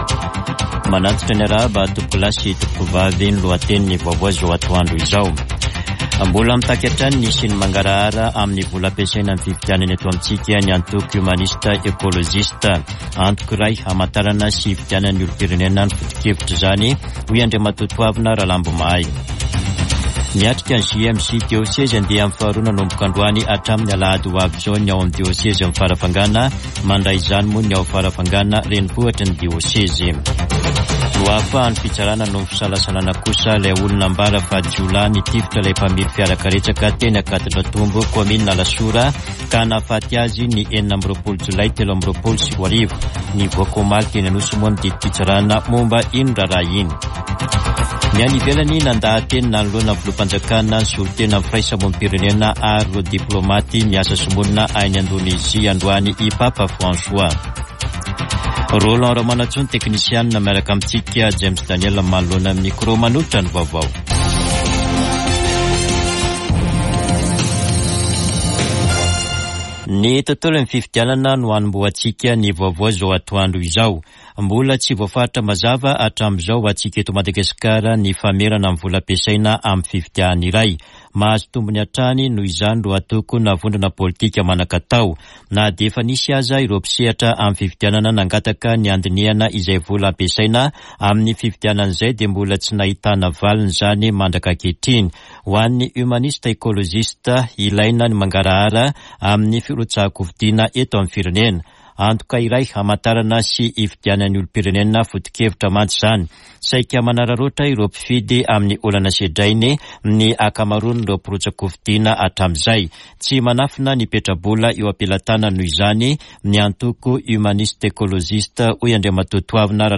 [Vaovao antoandro] Alarobia 4 septambra 2024